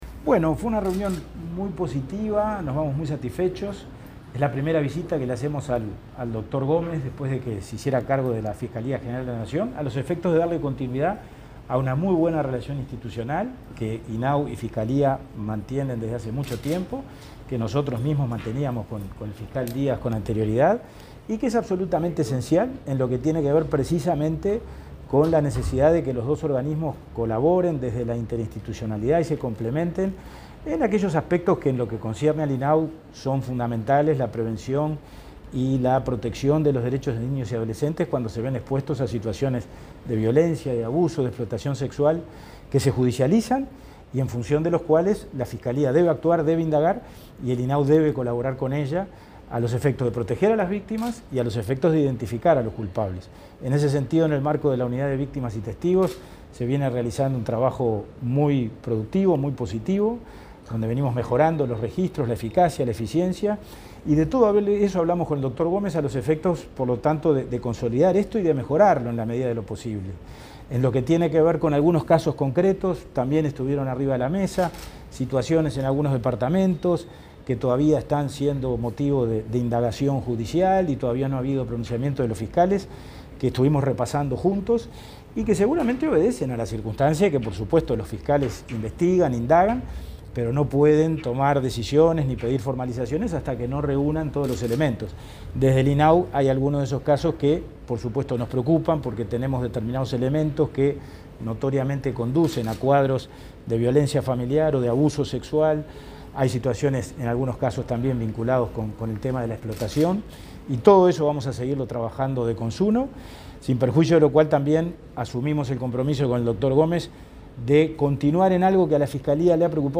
Declaraciones a la prensa del presidente del INAU, Pablo Abdala
Declaraciones a la prensa del presidente del INAU, Pablo Abdala 28/10/2021 Compartir Facebook X Copiar enlace WhatsApp LinkedIn Tras su reunión con el fiscal de Corte, Juan Gómez, este 28 de octubre, el presidente del Instituto del Niño y Adolescente del Uruguay, Pablo Abdala, efectuó declaraciones a la prensa.